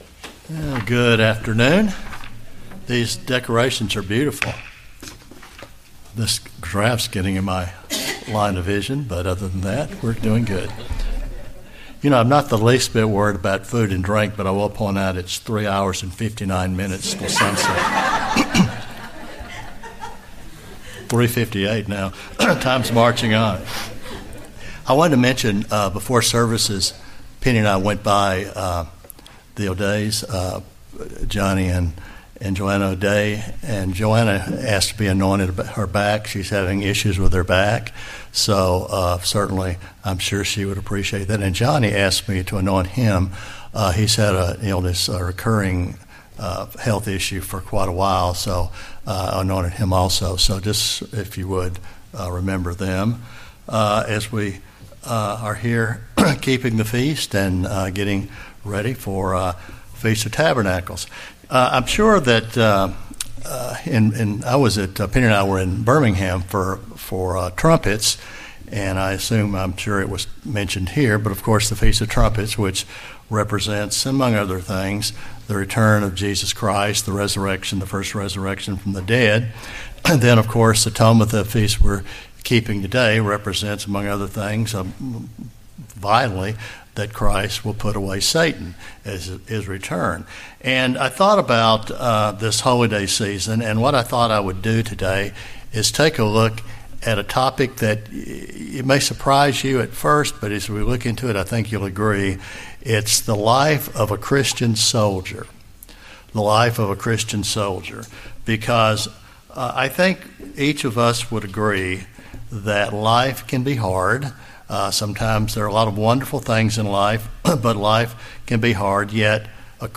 This sermon addresses those areas to be aware of.
Given in Huntsville, AL